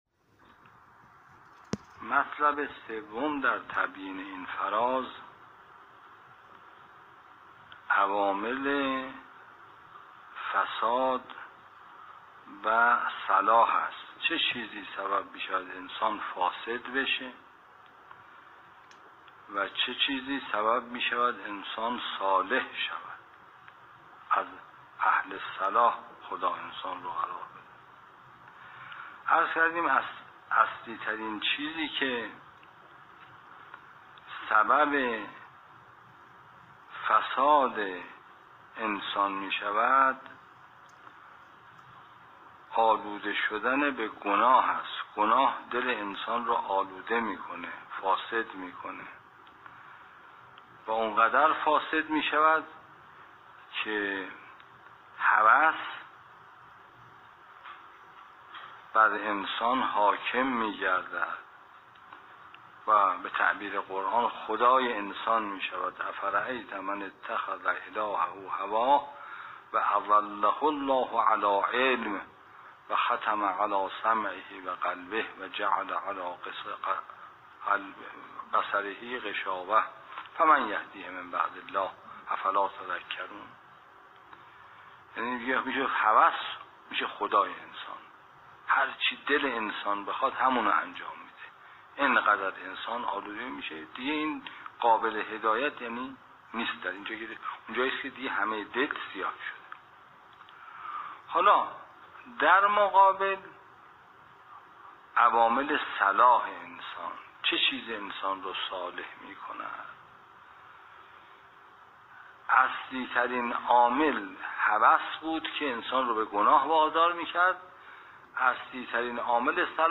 به گزارش خبرنگار خبرگزاری رسا، آیت الله محمد محمدی ری شهری نماینده مجلس خبرگان رهبری، امروز پیش از آغاز درس خارج فقه که در مدرسه علمیه حضرت عبدالعظیم حسنی(ع) برگزار شد، درباره عوامل فساد و صلاح گفت: اصلی ترین چیزی که سبب فساد انسان می شود آلوده شدن به گناه است.